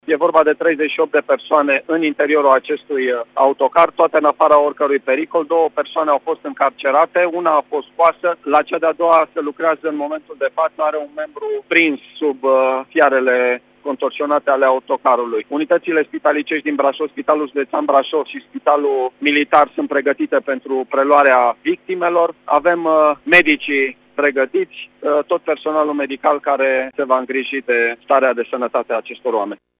Elicopterul de la SMURD Tîrgu Mureș este pregătit să intervină în cazul în care va fi solicitat, a declarat pentru Radio Tg. Mureș prefectul județului Brașov, Marian Rasaliu: